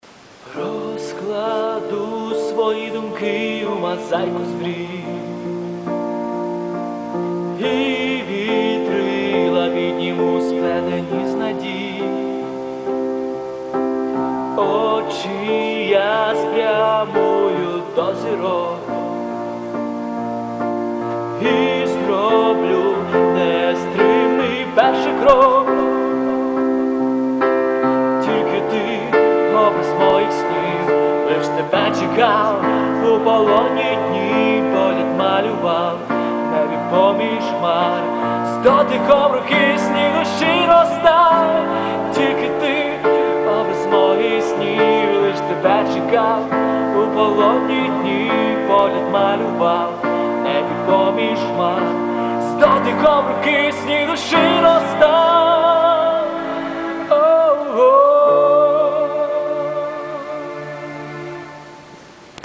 В нього гарний голос, гарна музика.